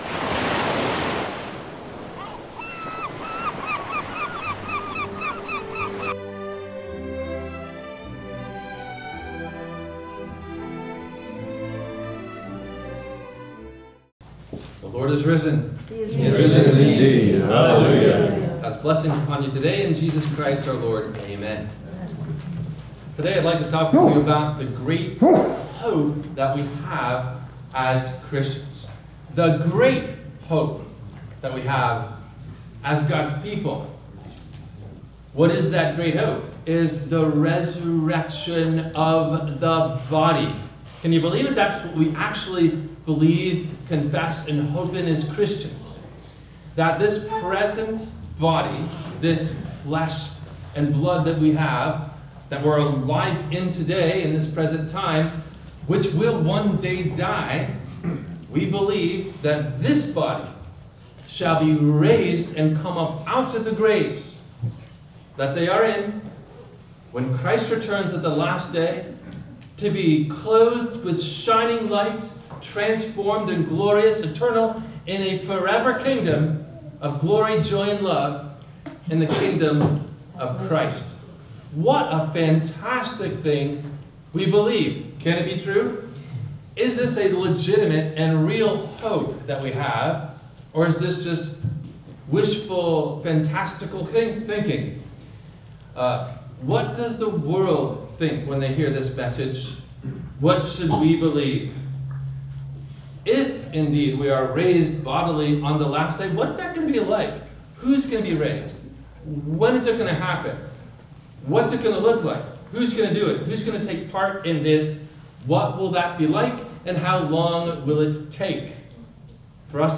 What Is The Hope In Which We Are Saved? – WMIE Radio Sermon – May 26, 2025
WMIE Radio – Christ Lutheran Church, Cape Canaveral on Mondays from 12:30 – 1:00